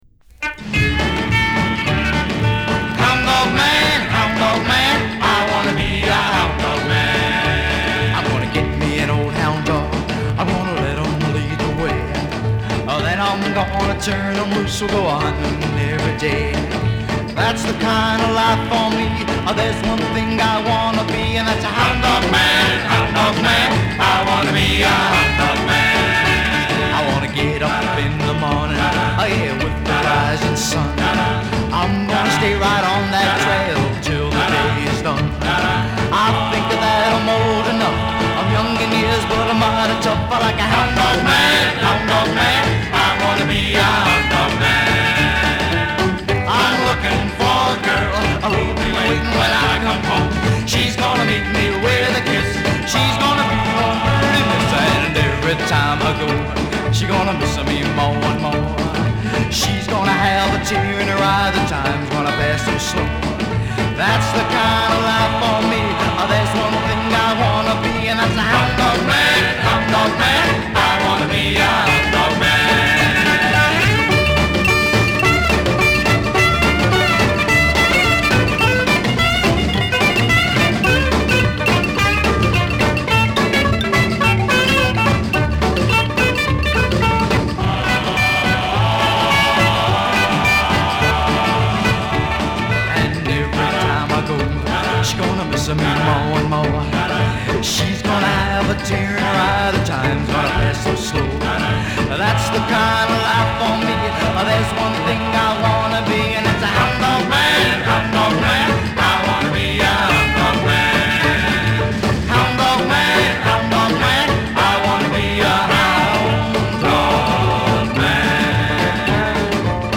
ラフなガレージ感覚のあるロッカー。アイドルとはいえ甘ったるさはゼロ。